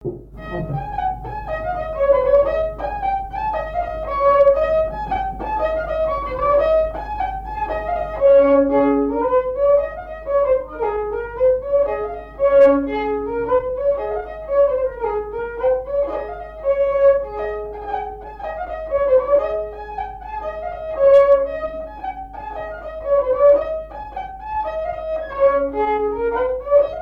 Saint-Martin-des-Tilleuls
danse : branle : avant-deux ;
Pièce musicale inédite